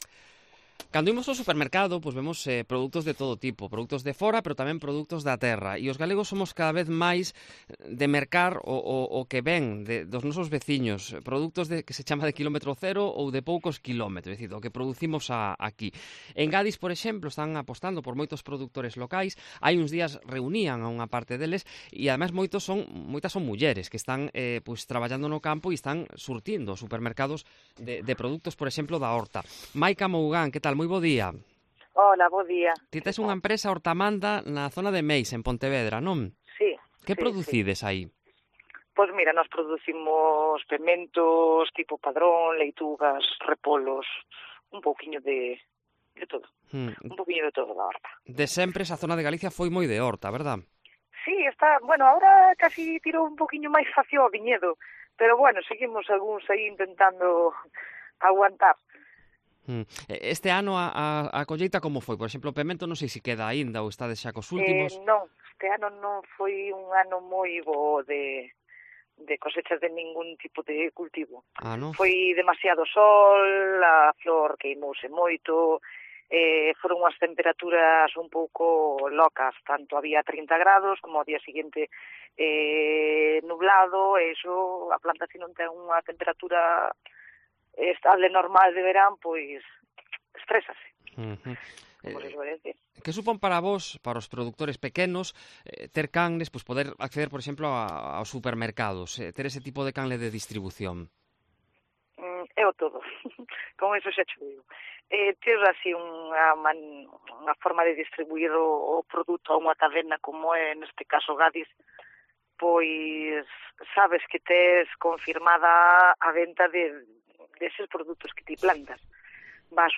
Lo comprobamos a las puertas de una frutería.